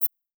techHum.wav